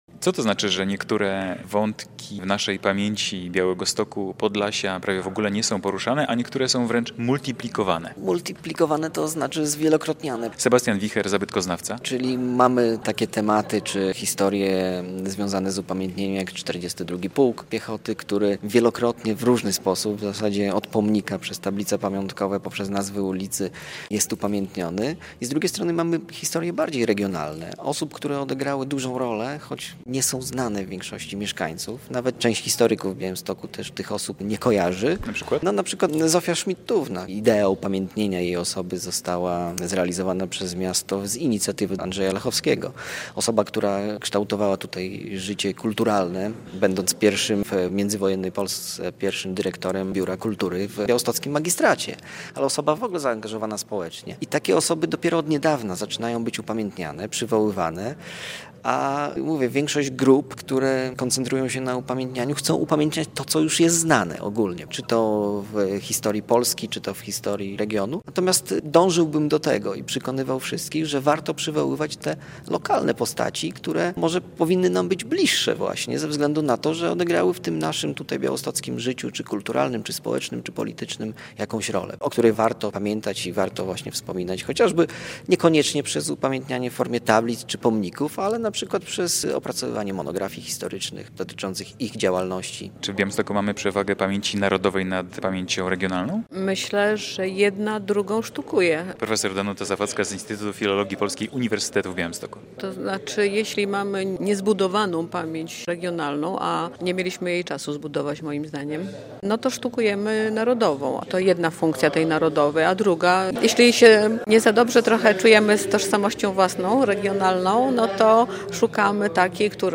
Warsztaty z publicznej dyskusji z cyklu "Oto czym jesteśmy/jesteście", cz. 1 - relacja
Spotkanie odbyło się w auli Uniwersytetu w Białymstoku przy Placu Uniwersyteckim.